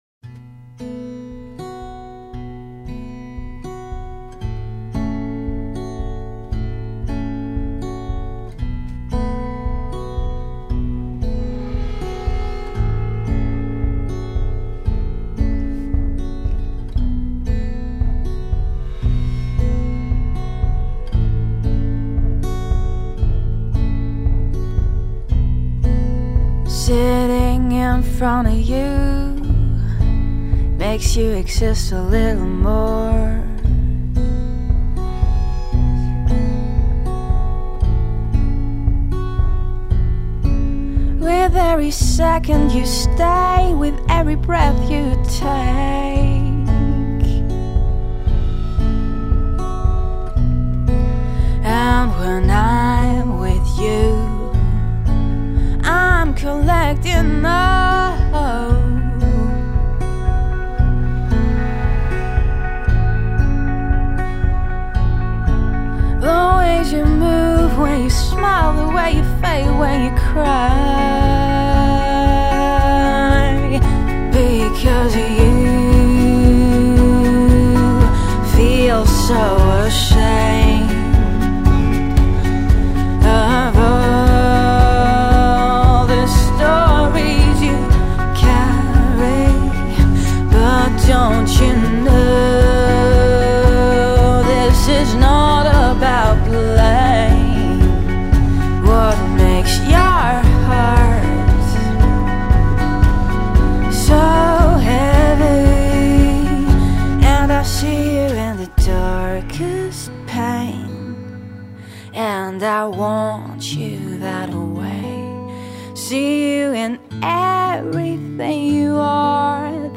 Singer-songwriter / Indie.